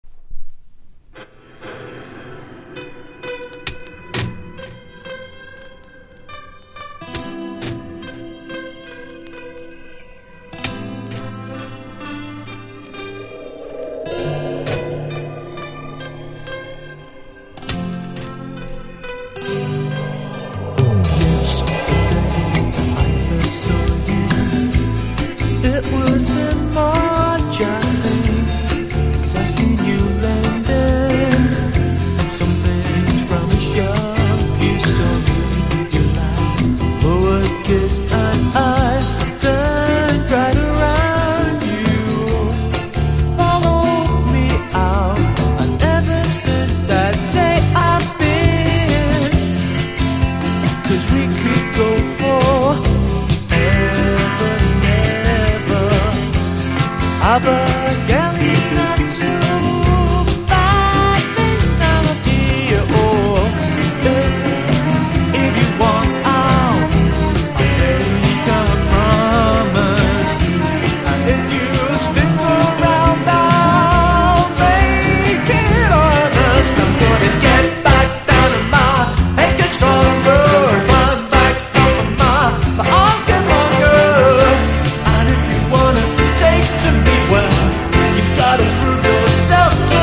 卓越したメロディセンスが最高なネオアコ大定番!!